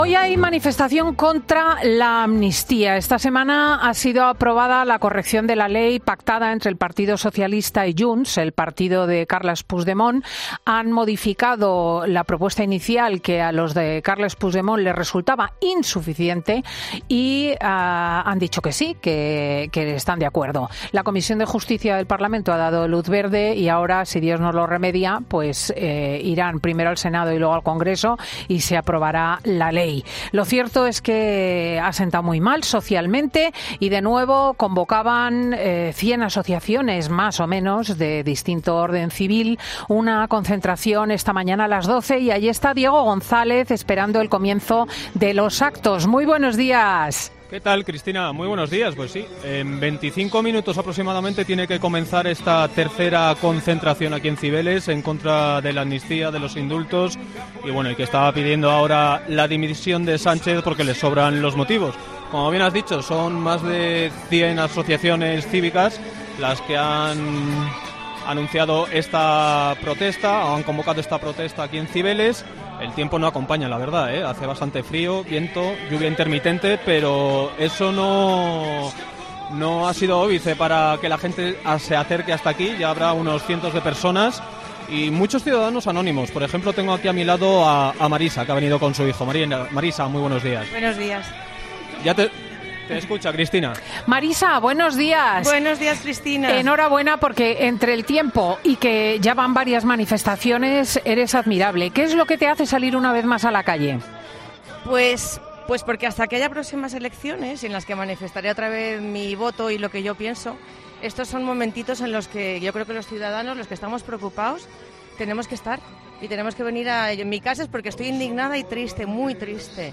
Este sábado hay manifestación contra la amnistía en Madrid y en COPE, te lo vamos contando todo